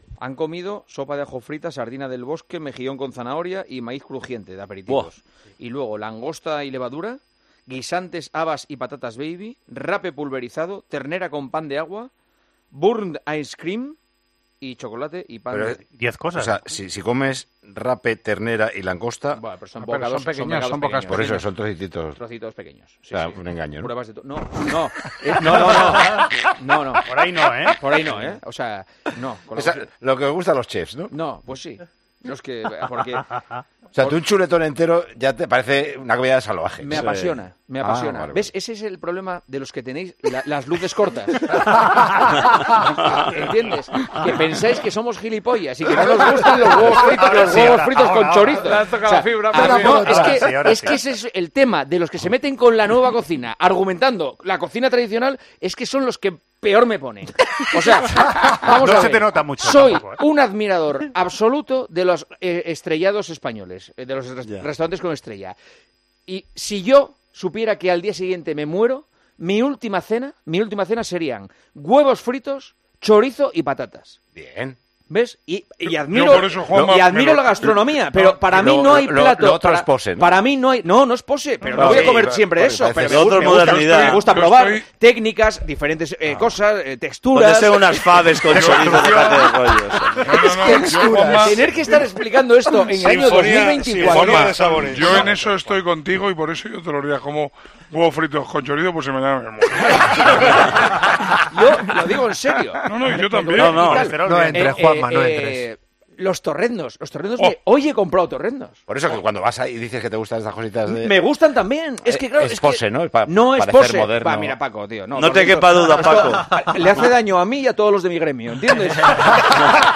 AUDIO: El director de El Partidazo de COPE expuso cuál sería la comida que elegiría por encima de cualquier otra.